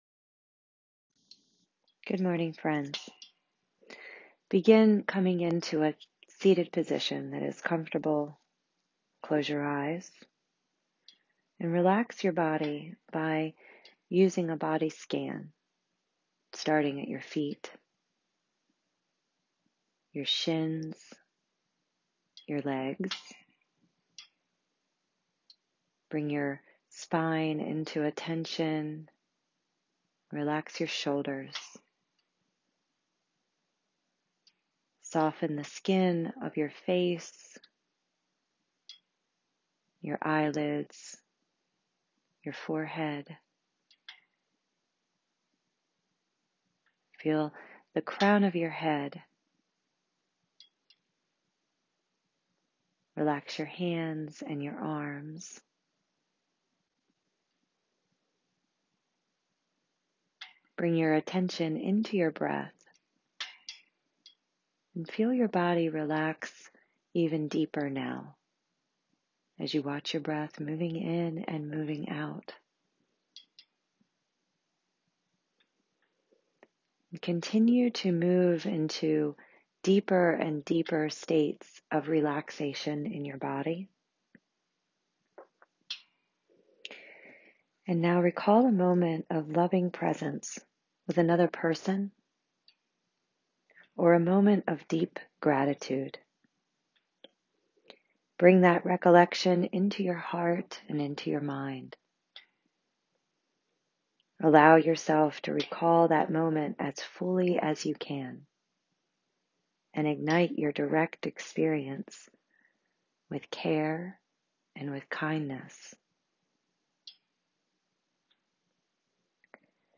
Loving Kindness Meditation